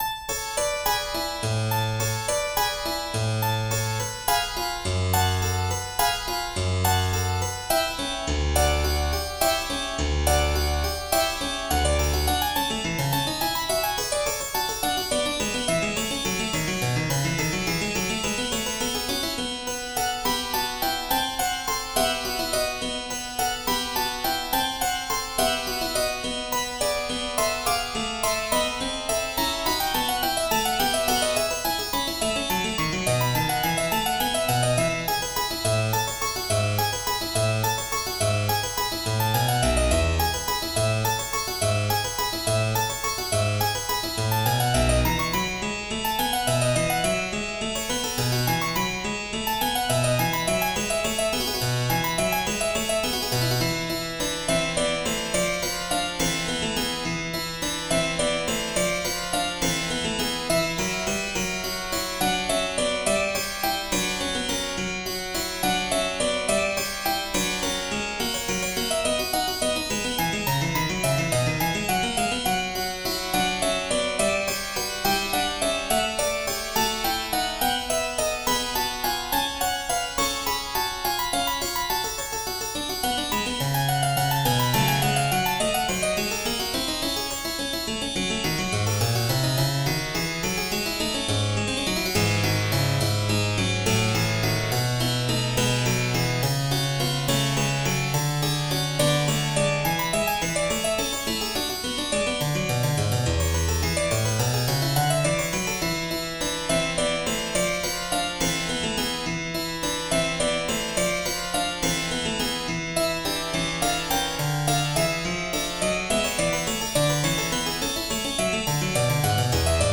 in A Major: Allegro